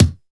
Index of /90_sSampleCDs/Roland - Rhythm Section/KIK_Natural Kick/KIK_Natural K2
KIK STRENG0I.wav